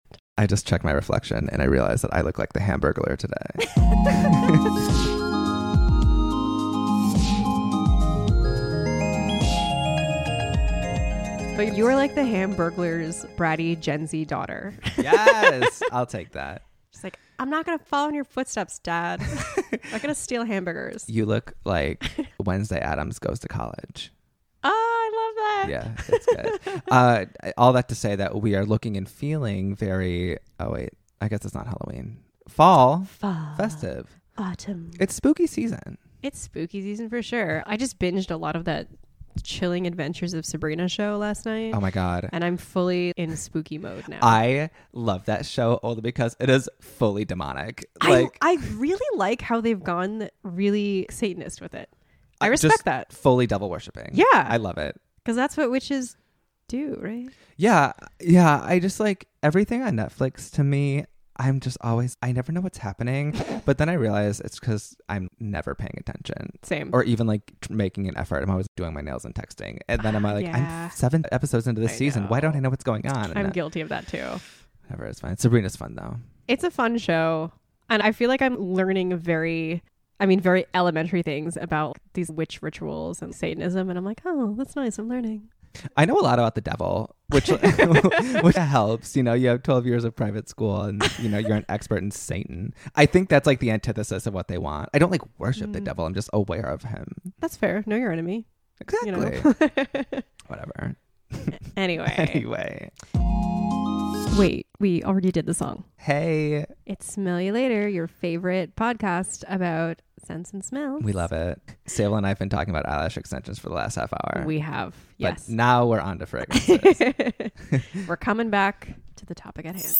This episode has everything: Satanism, cinnamon-flavored colonialism, misogynistic food groups, and the Hamburglar. We take it upon ourselves to do a two-person roundtable of all things pumpkin spice and its reign over autumn in the North American hemisphere.